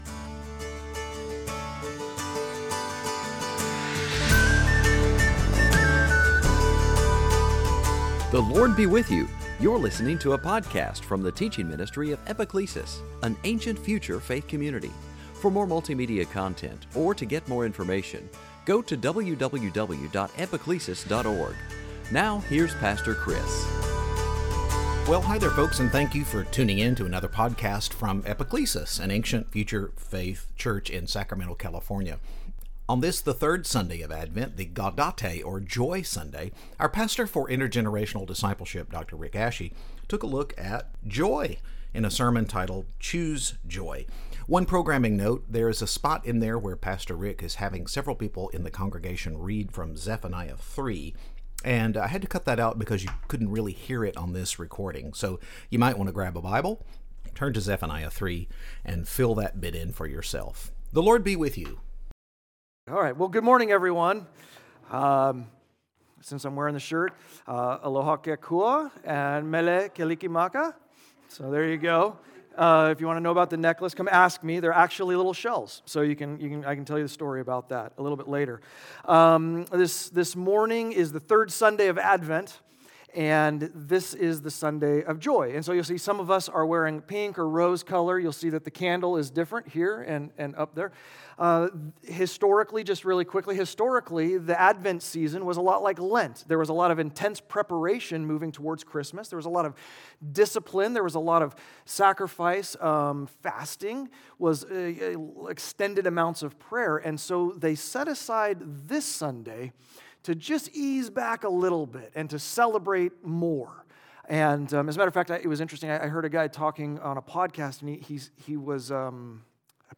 2018 Sunday Teaching choice happiness hope joy missing tile Advent